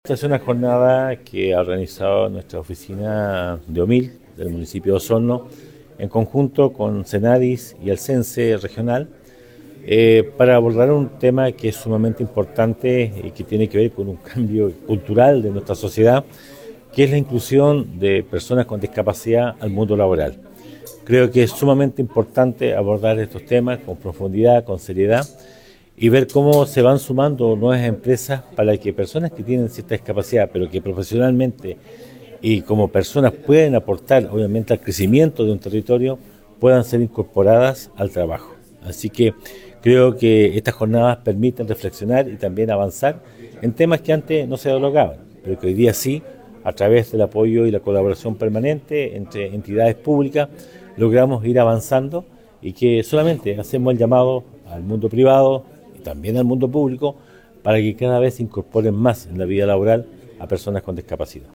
Por su parte el Alcalde de Osorno, Emeterio Carrillo indicó que es de suma importancia abordar estas temáticas de forma seria, para incorporar a las personas con discapacidad.